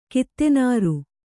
♪ kittenāru